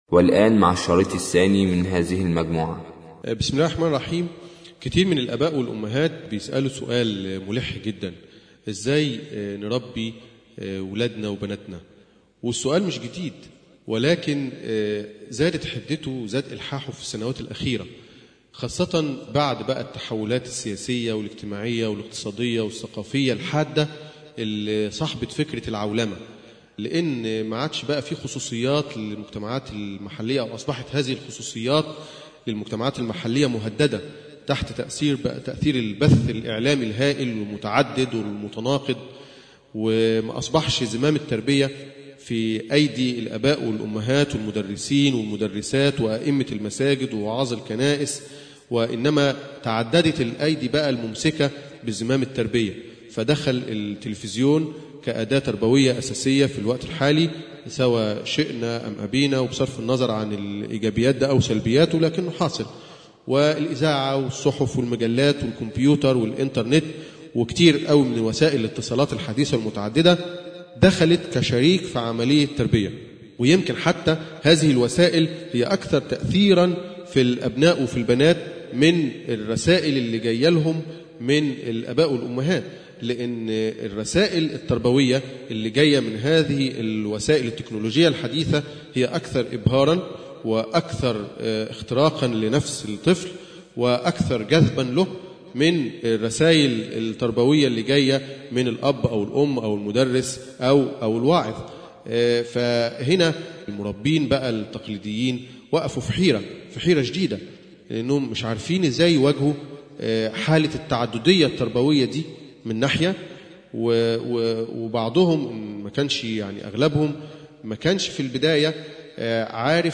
الدرس الثاني - كيف تنشئ طفلا ناجحا - قسم المنوعات